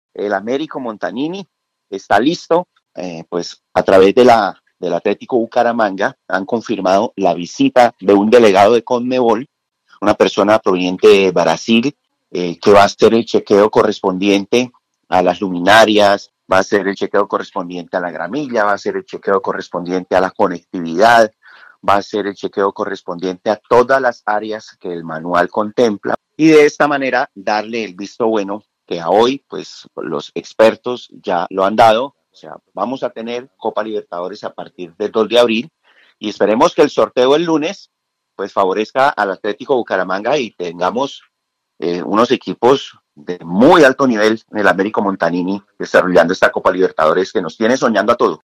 Ariel Fernando Rojas, Director de Indersantander